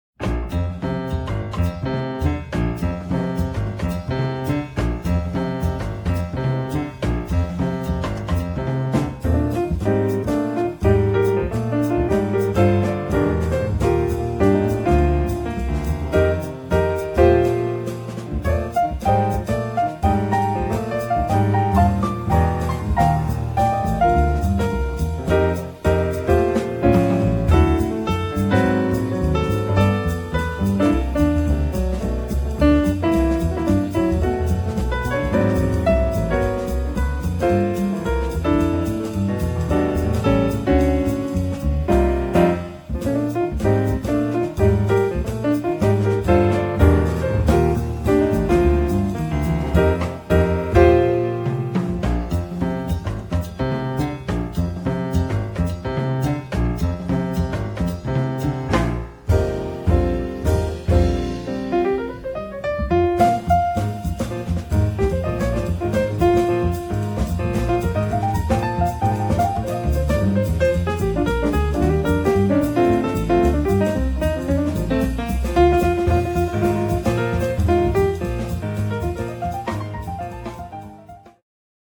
piano
bass
drums